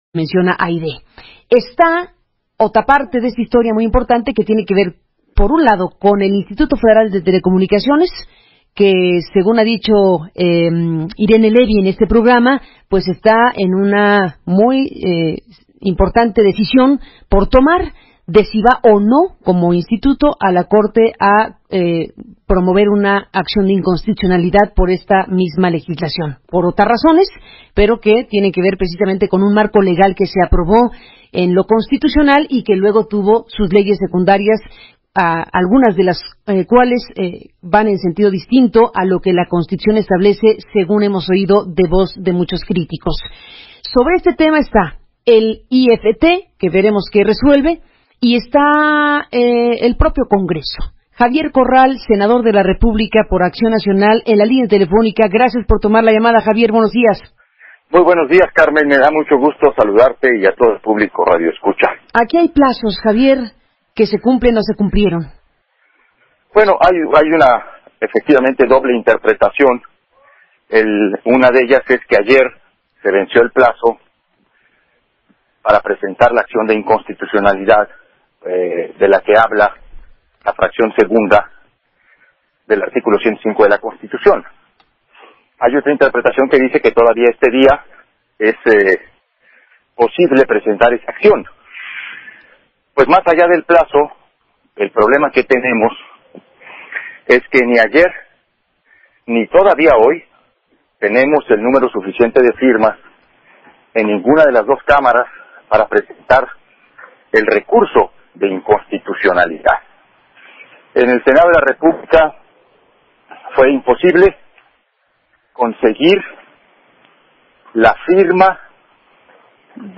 Sen. Javier Corral en entrevista con Carmen Aristegui